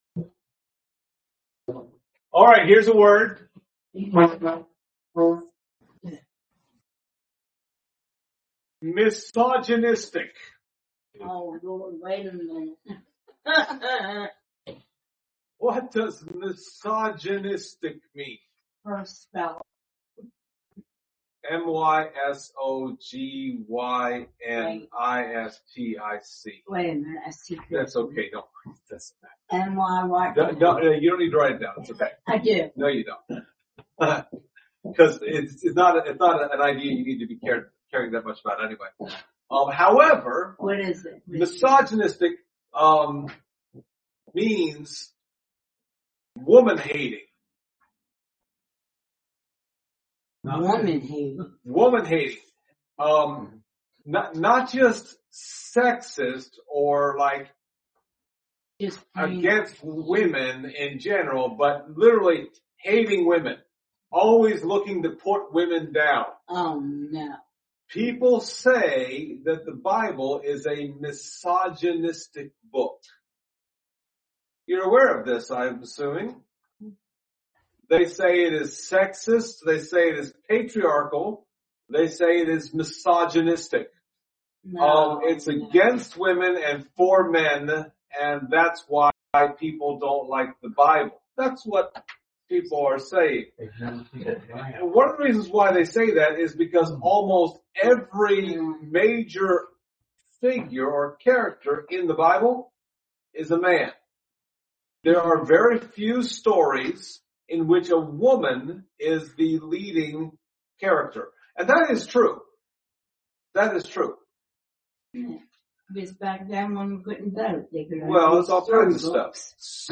Service Type: Sunday Morning Topics: evangelism , Lydia , misogyny , sovereignty